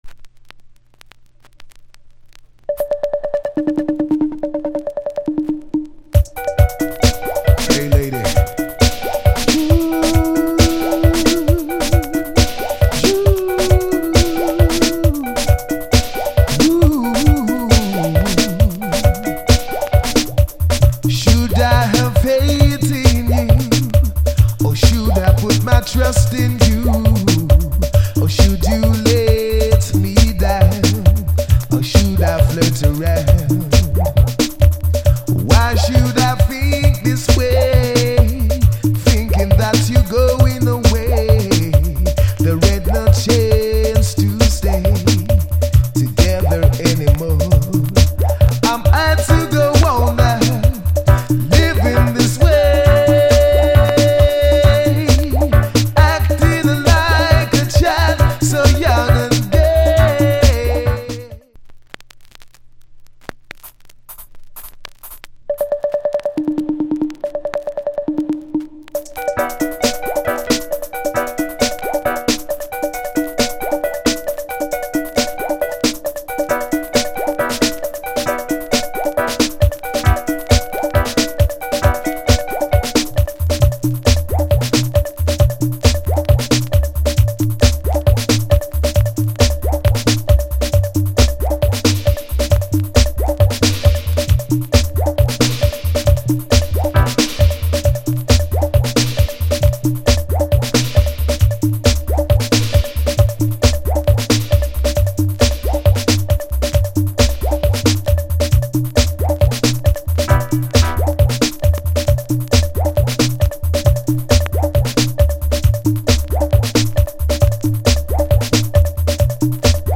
90's Dance Hall Hit!
riddim